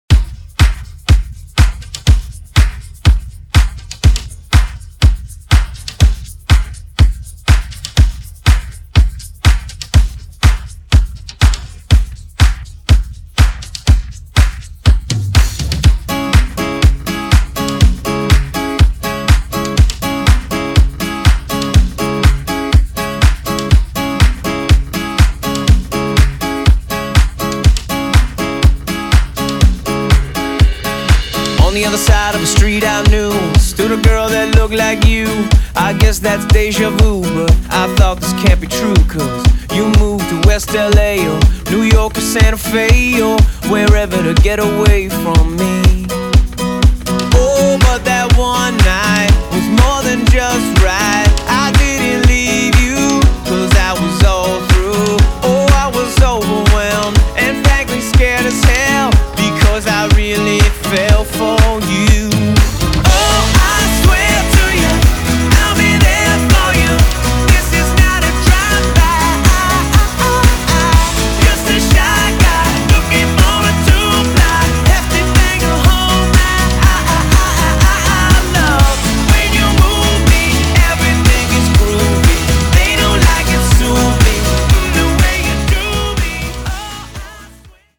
Genres: 80's , DANCE , RE-DRUM
Clean BPM: 115 Time